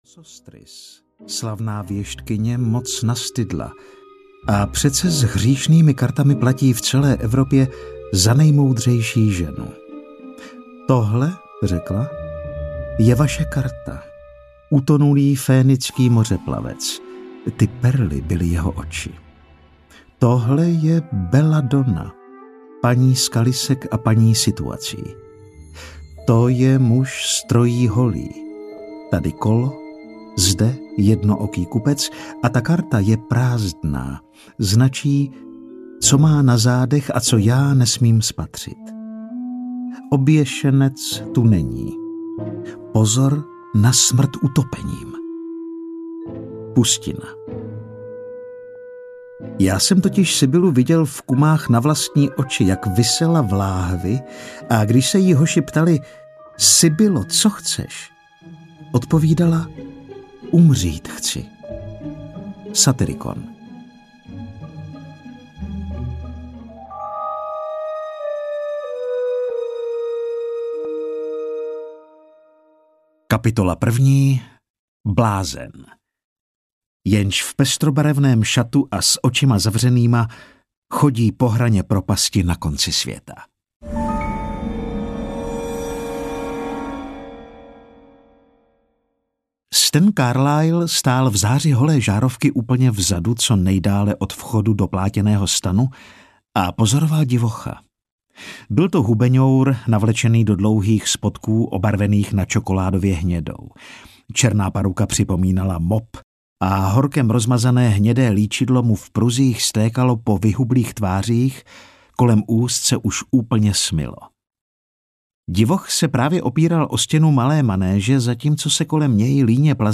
Ulička přízraků audiokniha
Ukázka z knihy
ulicka-prizraku-audiokniha-0